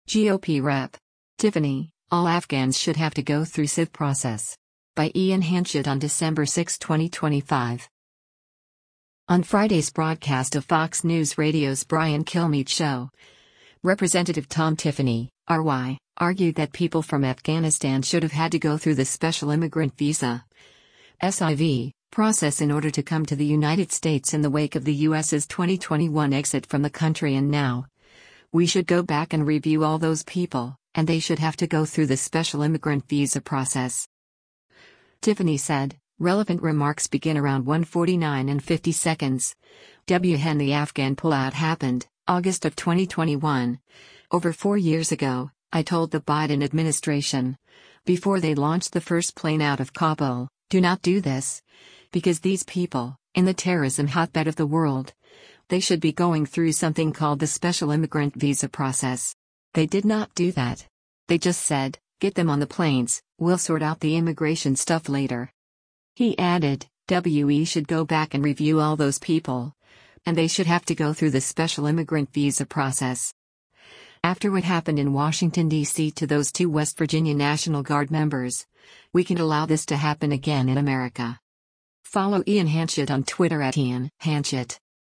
On Friday’s broadcast of Fox News Radio’s “Brian Kilmeade Show,” Rep. Tom Tiffany (R-WI) argued that people from Afghanistan should have had to go through the Special Immigrant Visa (SIV) process in order to come to the United States in the wake of the U.S.’s 2021 exit from the country and now, we “should go back and review all those people, and they should have to go through the Special Immigrant Visa process.”